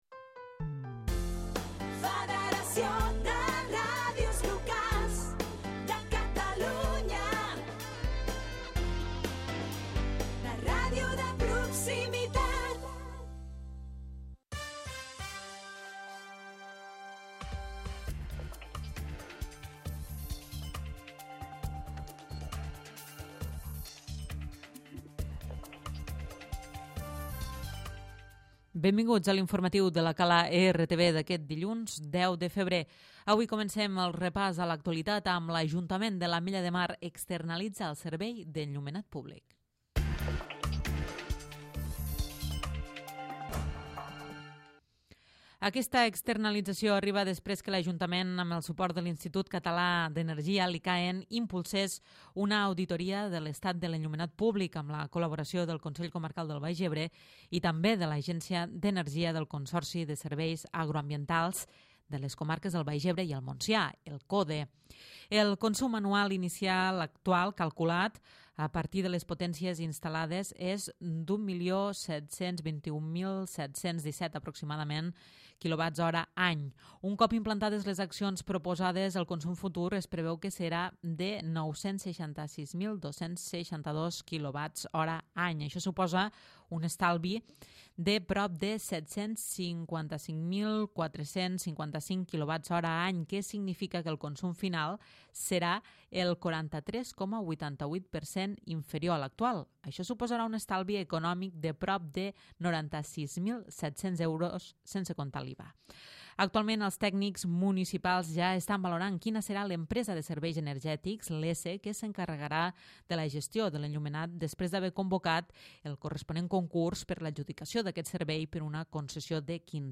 Butlletí infromatiu